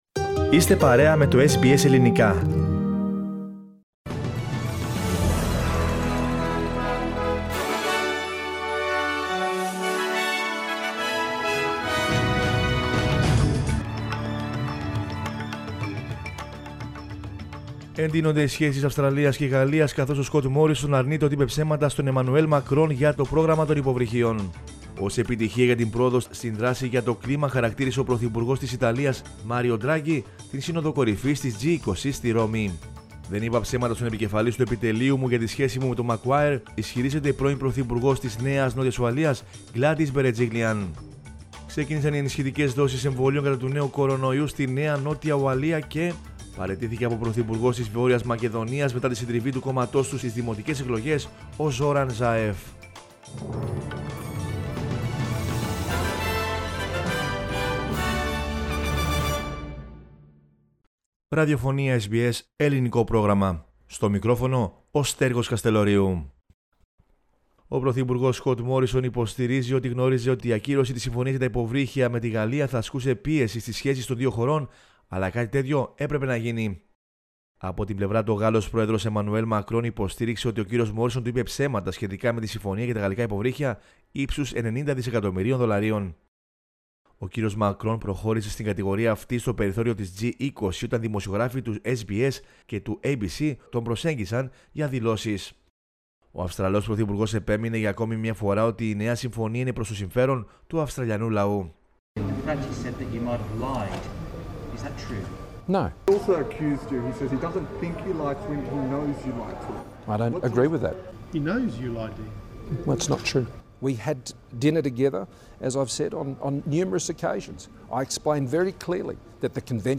News in Greek from Australia, Greece, Cyprus and the world is the news bulletin of Monday November 1 2021.